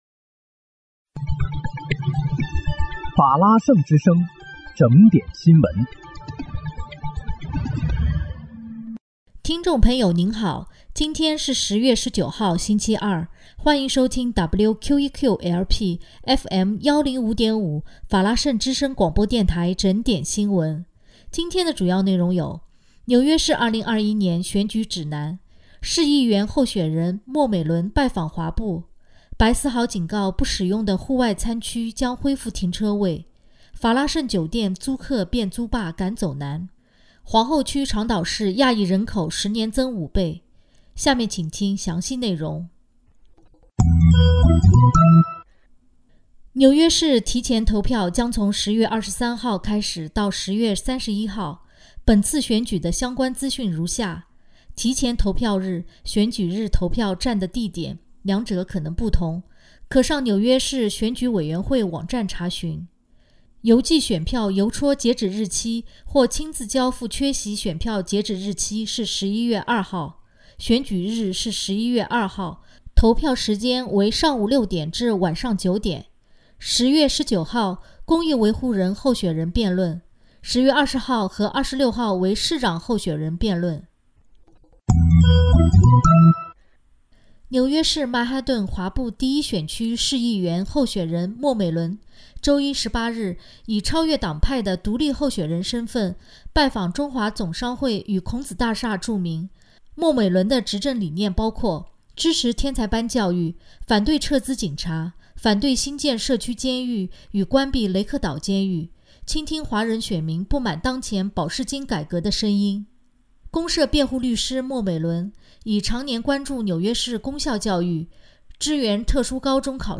10月19日（星期二）纽约整点新闻